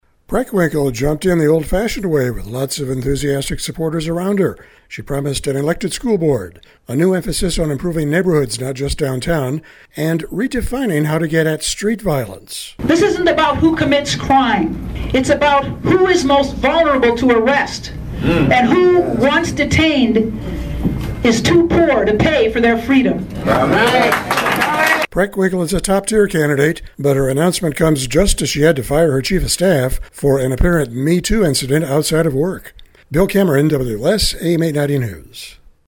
Preckwinkle jumped in the old fashioned way with lots of enthusiastic supporters around her.